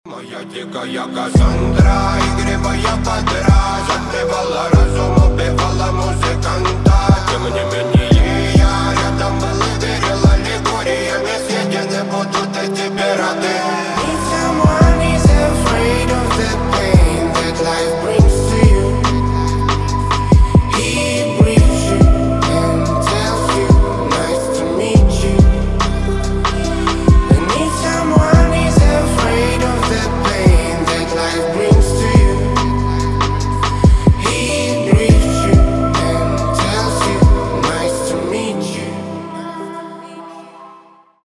Рэп рингтоны , Романтические рингтоны
Грустные , Басы , Атмосферные